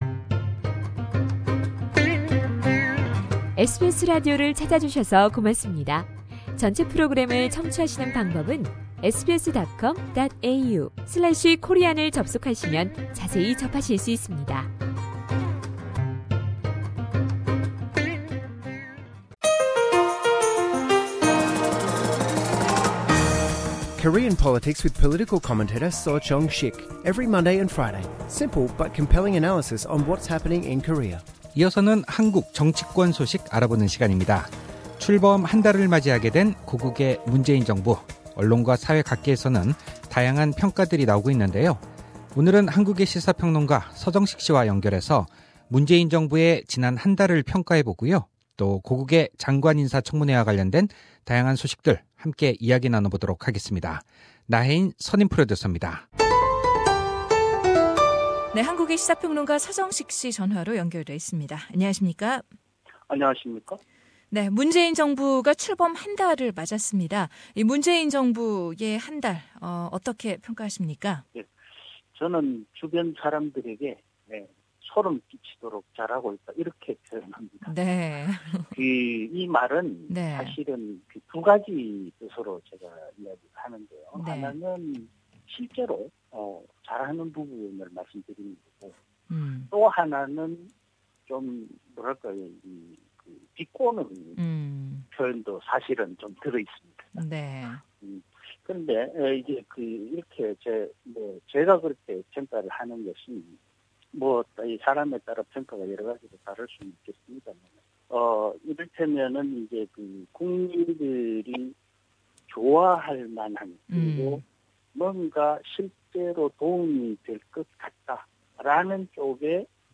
이번 결정이 한중-한미 관계에 미치는 실질적인 영향에 대해 알아봅니다. 상단의 재생 버튼을 누르시면 전체 인터뷰를 들으실 수 있습니다.